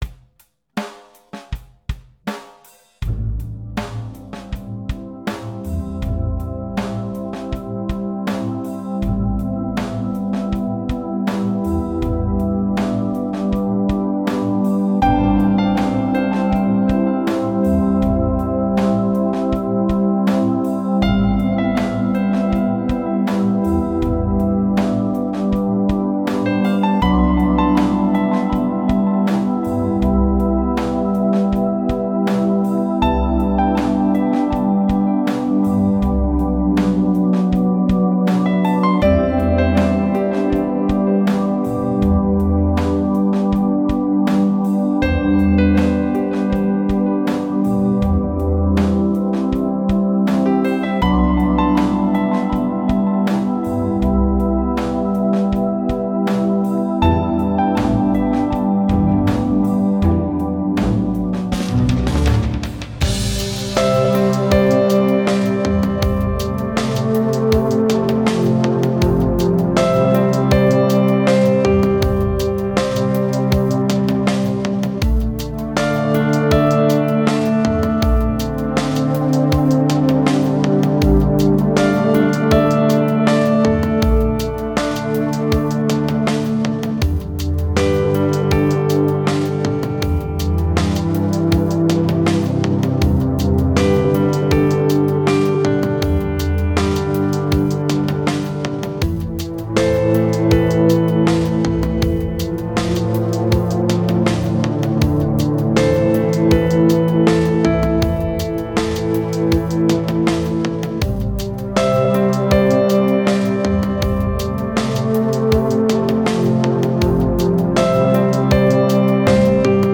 ορχηστρικές συνθέσεις
Lounge & Calm διάθεση